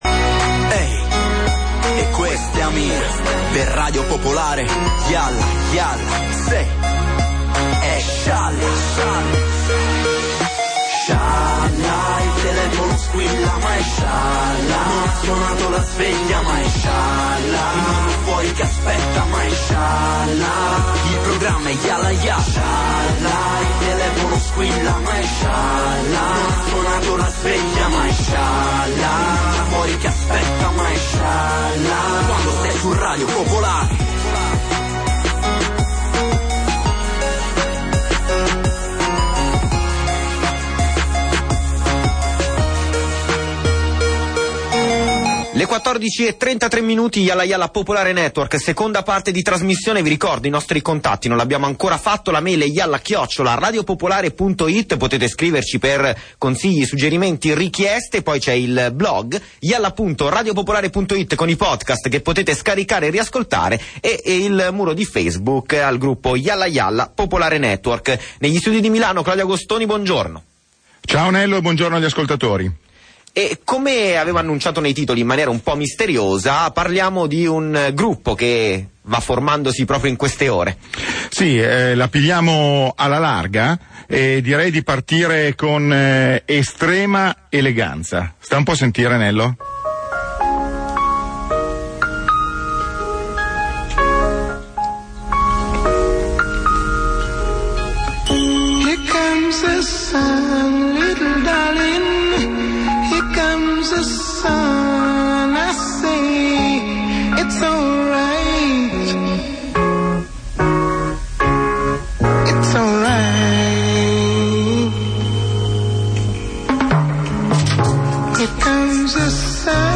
Jalla! Jalla! di Radio Popolare, condotta in studio a Milano